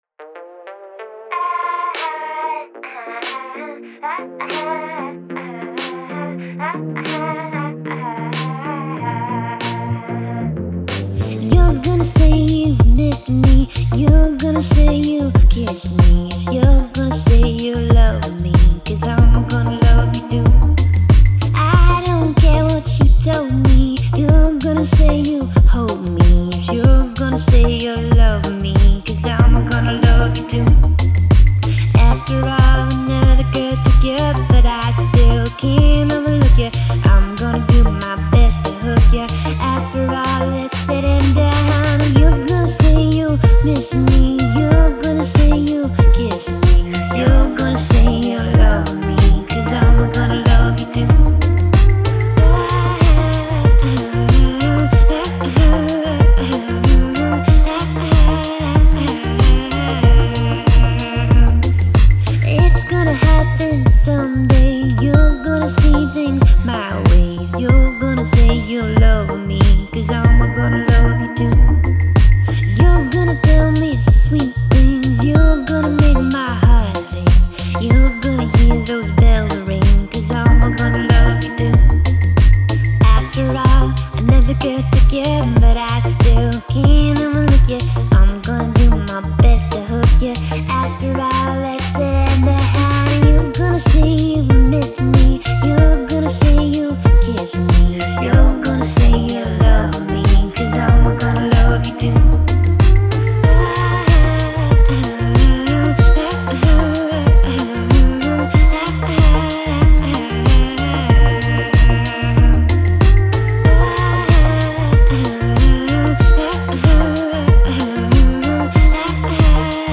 Tag: Electronic Pop